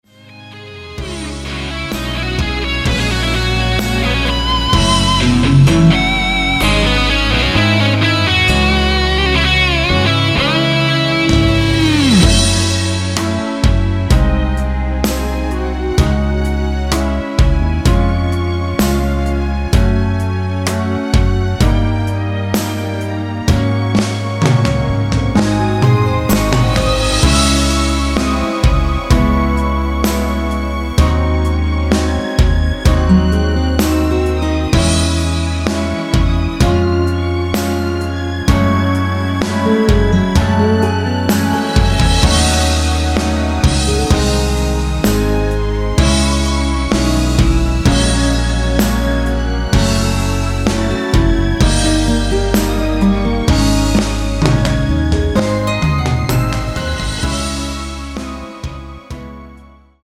C#m
앞부분30초, 뒷부분30초씩 편집해서 올려 드리고 있습니다.